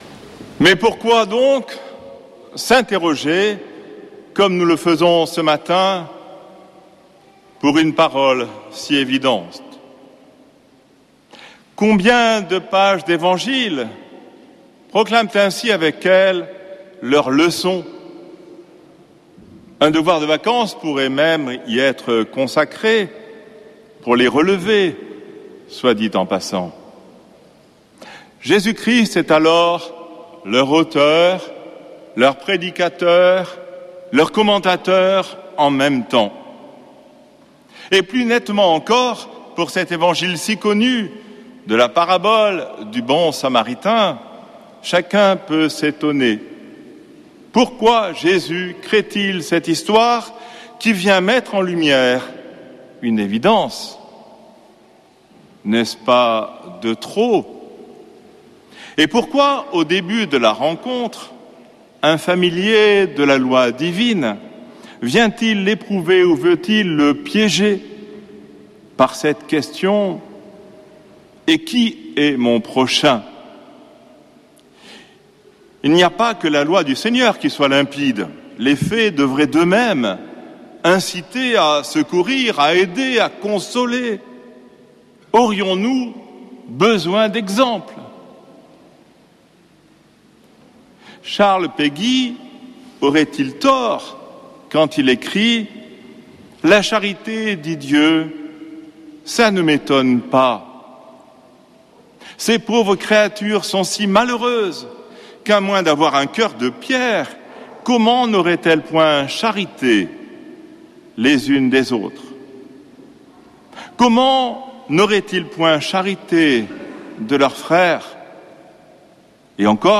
dimanche 13 juillet 2025 Messe depuis le couvent des Dominicains de Toulouse Durée 01 h 30 min